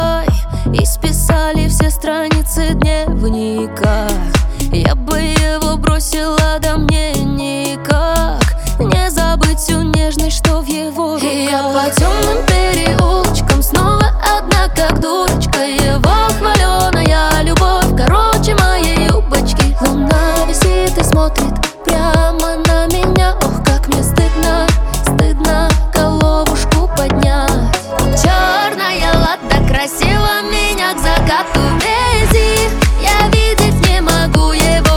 Жанр: Русская поп-музыка / Поп / Русский рок / Русские